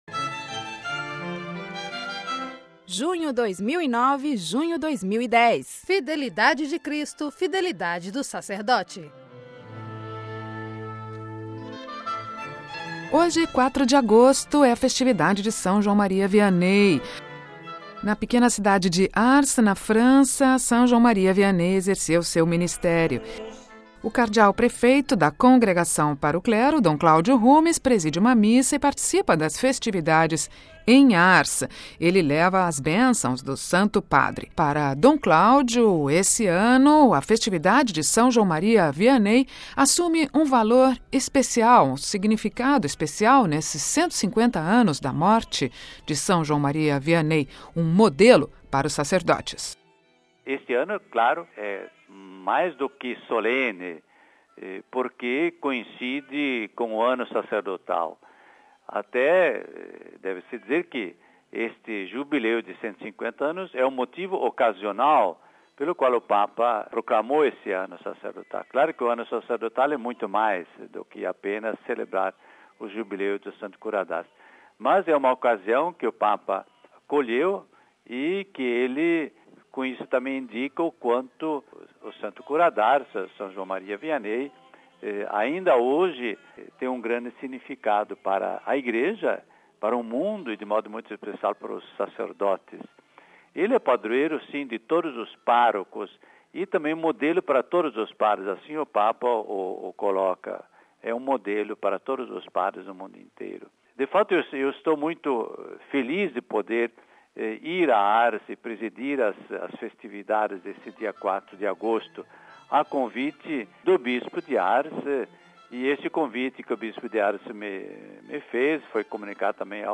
O Cardeal Claudio Hummes, que completa 75 anos no próximo dia 8 de agosto, concedeu entrevista ao Programa Brasileiro.